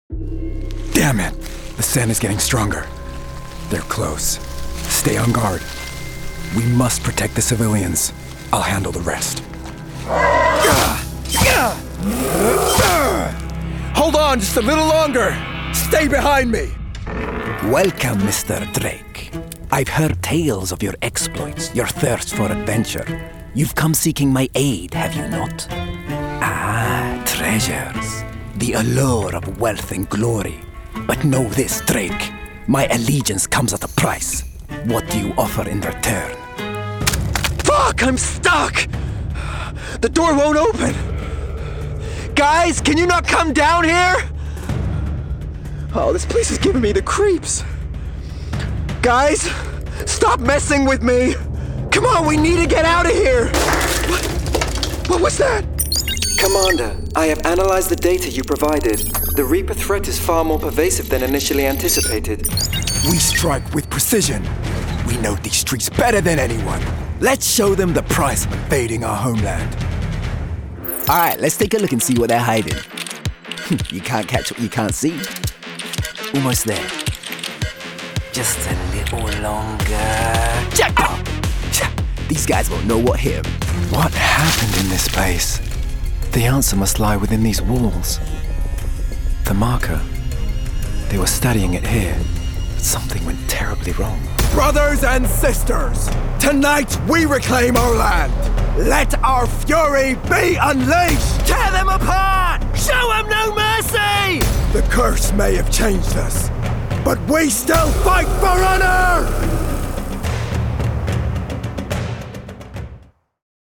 Gaming Showreel
Male
Confident
Cool
Friendly